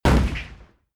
boxmove.wav